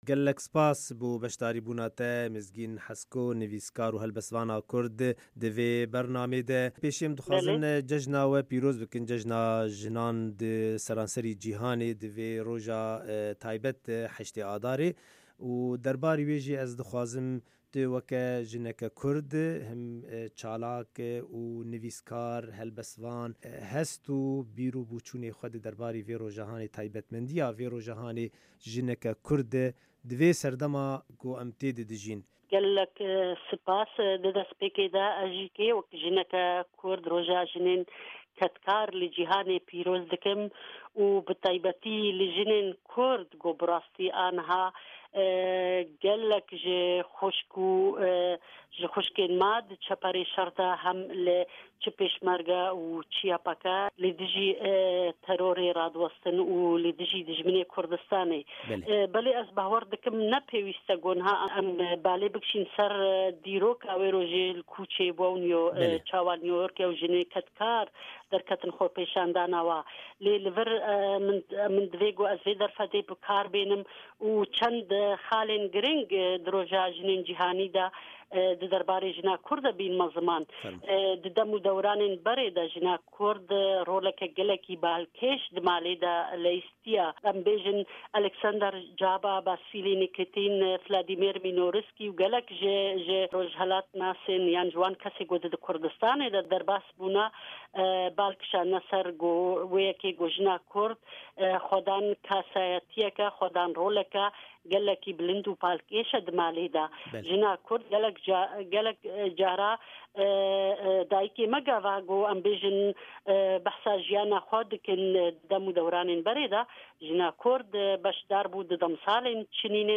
Hevpeyvîna